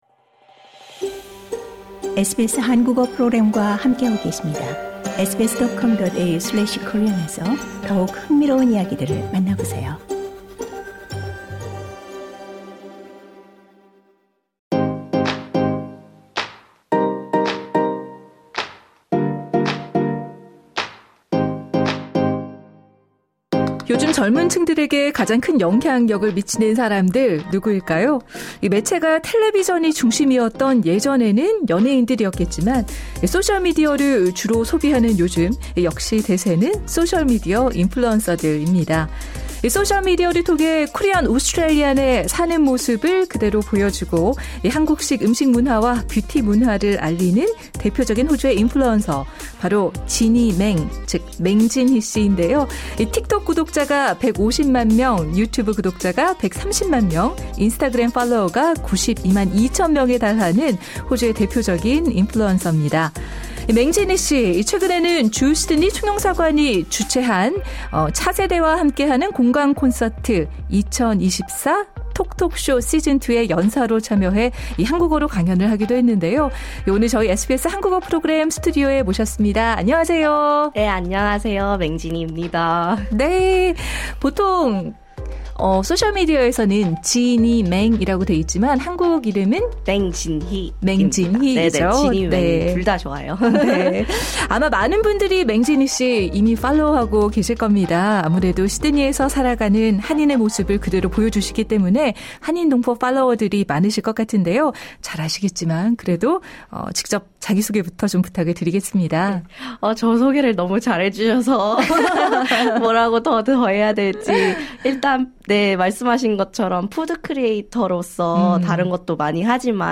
2024 TOP 5 인터뷰 5위
오늘 저희 SBS 한국어 프로그램 스튜디오에 모셨습니다.